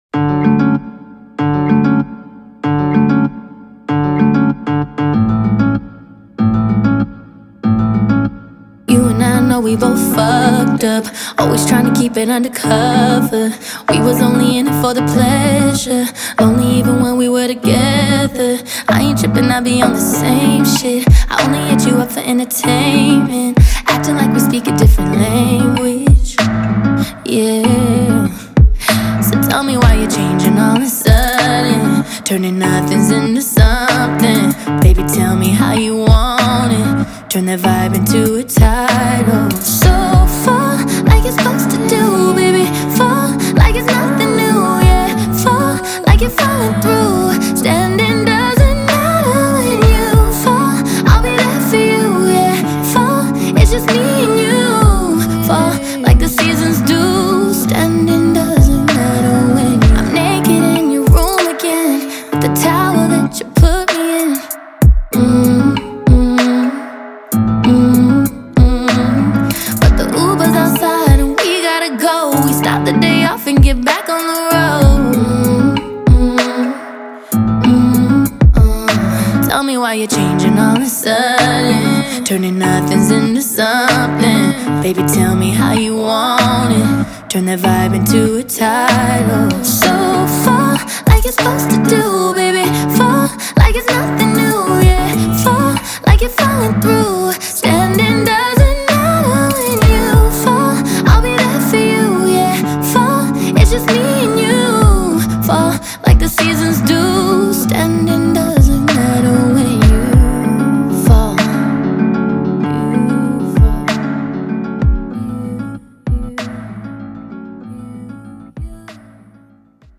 это яркая и энергичная песня в жанре R&B и поп